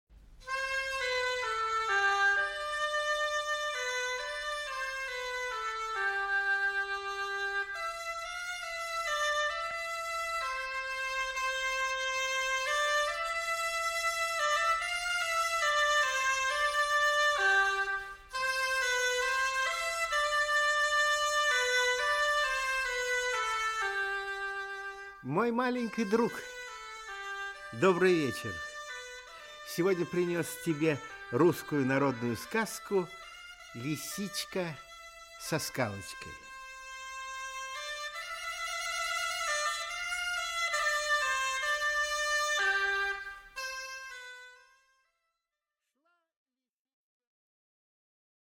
Aудиокнига Лисичка со скалочкой Автор Народное творчество Читает аудиокнигу Николай Литвинов.